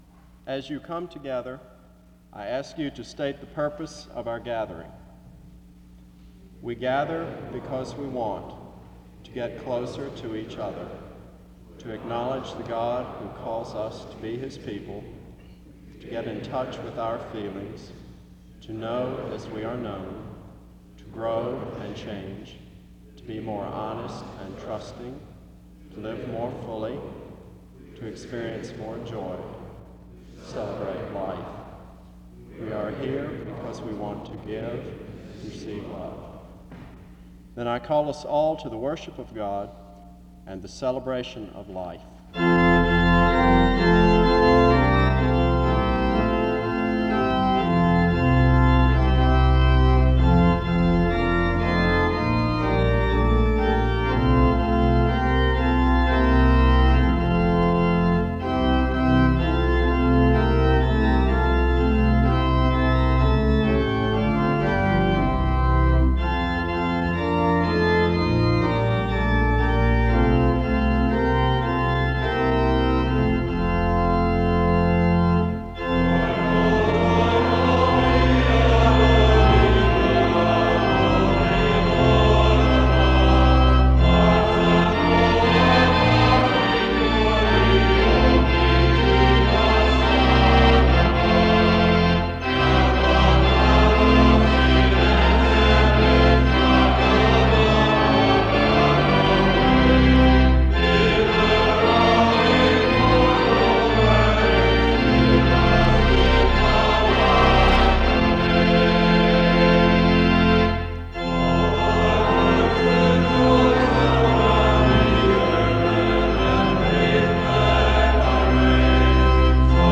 Download .mp3 Description The service starts with reading and music from 0:00-4:08. A time of prayer takes place from 4:17-5:30.
A prayer is offered from 21:48-24:41. Romans 8:3-8 and Romans 13:3-10 is read from 24:51-26:25. Music plays from 26:32-28:38.
A responsive reading and music take place from 36:15-37:48.